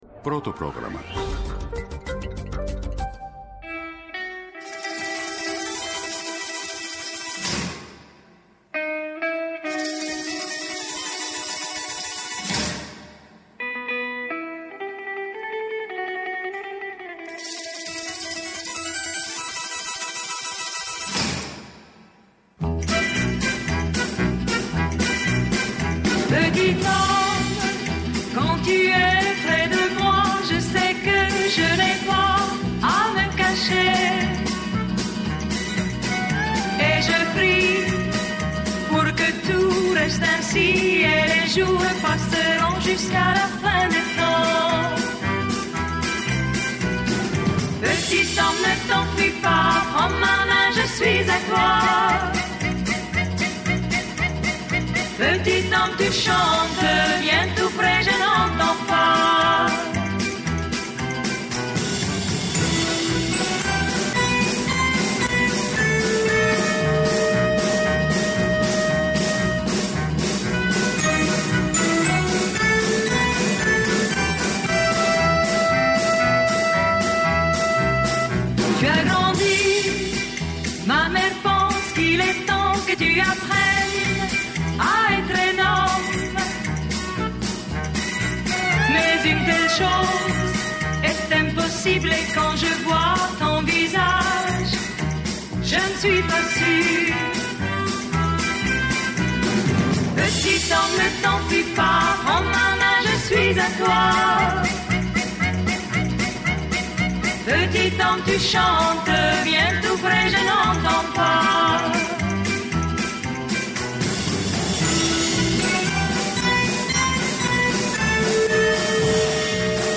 Aπόψε στο Doc On Air καλεσμένοι οι δημιουργοί κόμικς